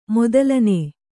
♪ modalanē